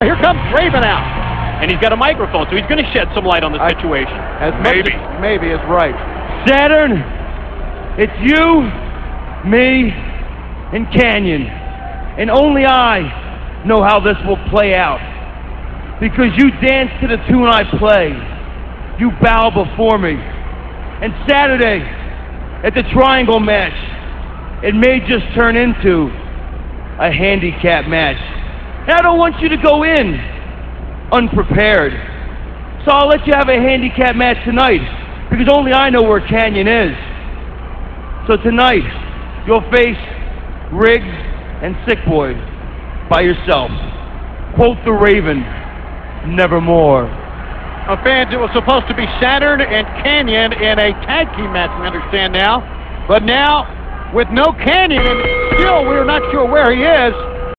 - This speech comes Thunder - [7.5.98]. This speech is essentially Raven taunting Saturn before their match at Road Wild.